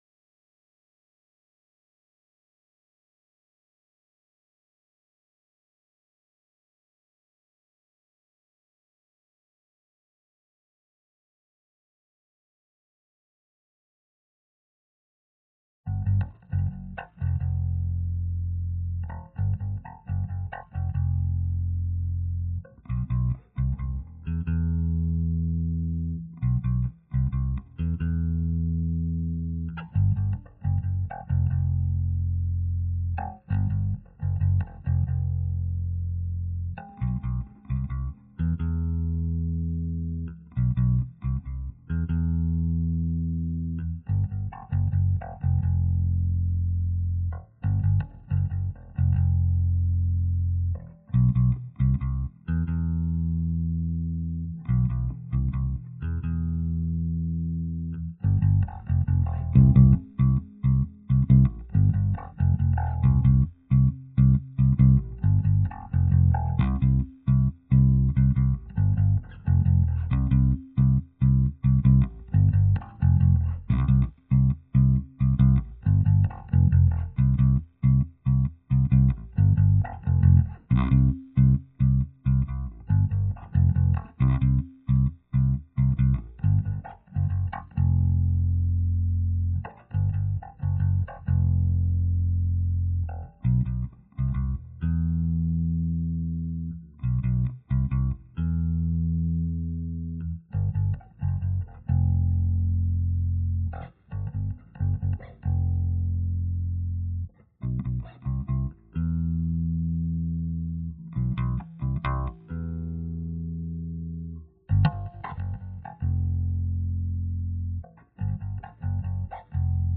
Bass Mixdown.wav